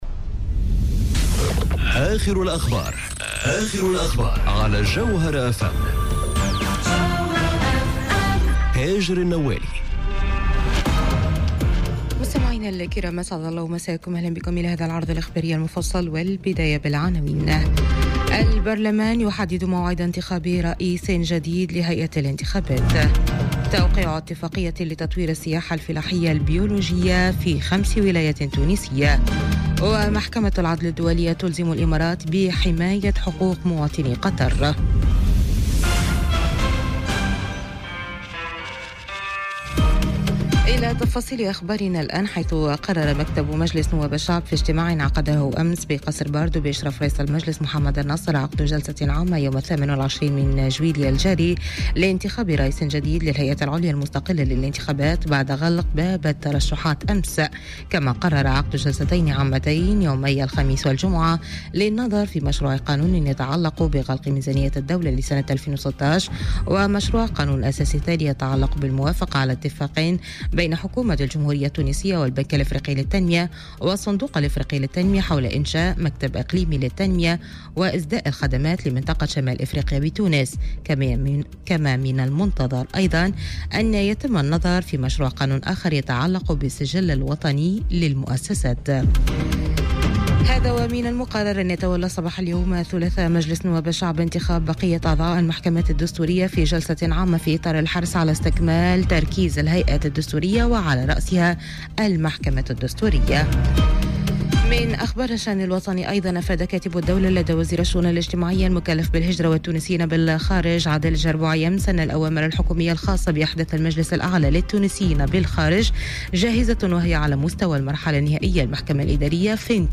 نشرة أخبار منتصف الليل ليوم الثلاثاء 24 جويلية 2018